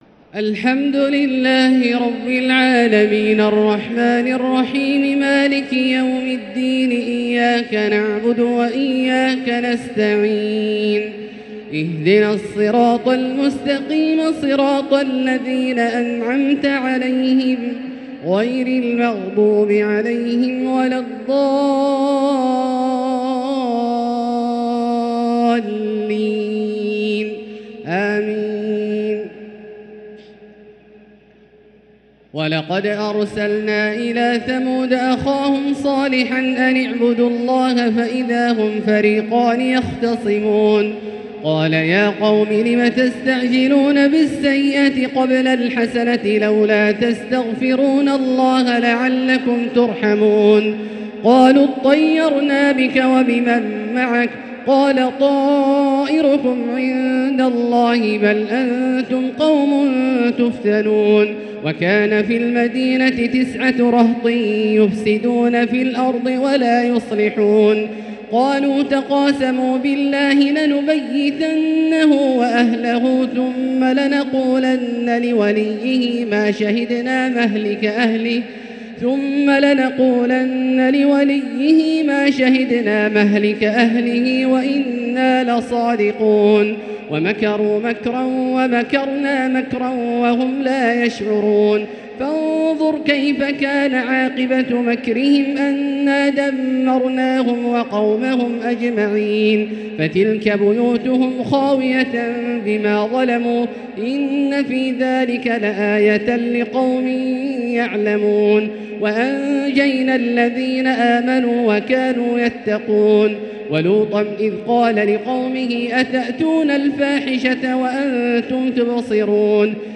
تهجد ليلة 23 رمضان 1444هـ من سورتي النمل (45-93) و القصص كاملة | Tahajjud 23th night Ramadan 1444H Surah An-Naml and Al-Qasas > تراويح الحرم المكي عام 1444 🕋 > التراويح - تلاوات الحرمين